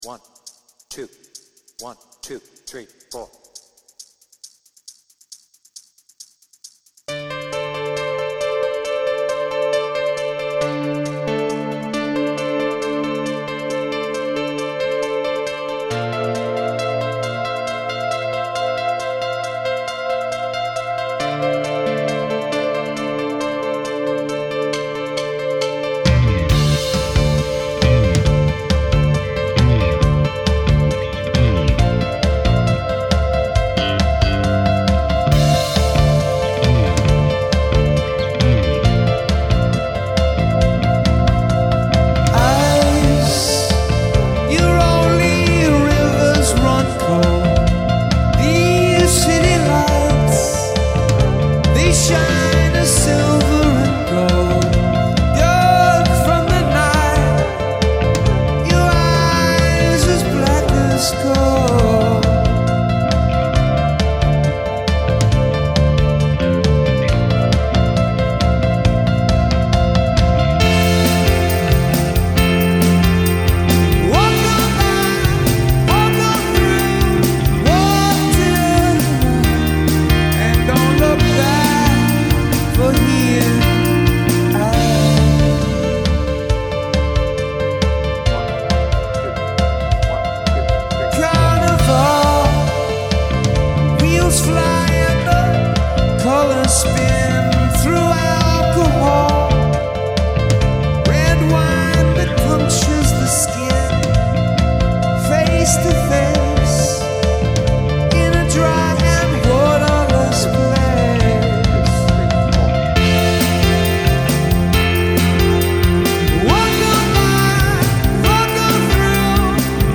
BPM : 136